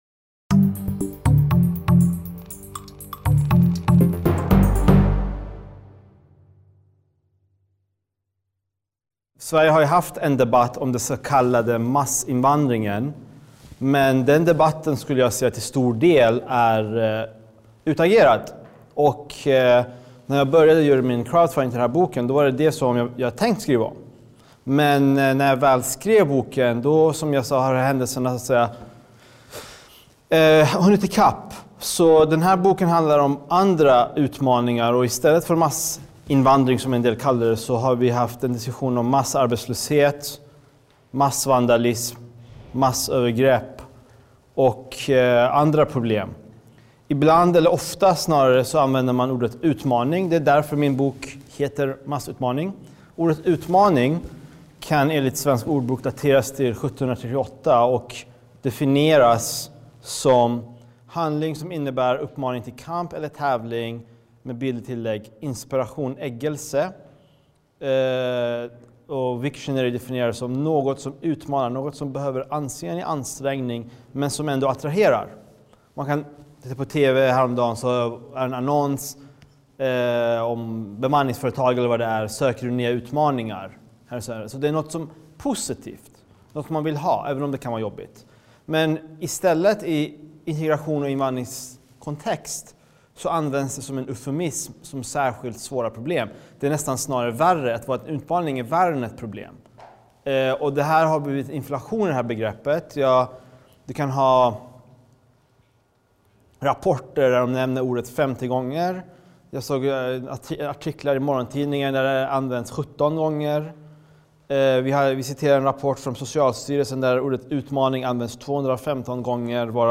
Onsdagen den 1 februari var nationalekonomen Tino Sanandaji inbjuden till Sveriges riksdag för att presentera sin bok Massutmaning: Ekonomisk politik mot utanförskap och antisocialt beteende. Han var inbjuden av riksdagsledamöterna Staffan Danielsson (C) och Jan Ericson (M).